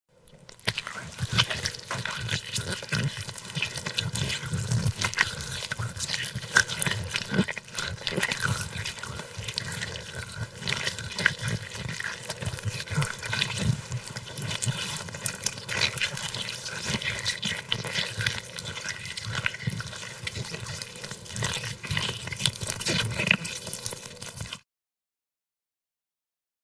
Звук выхода червяка из плоти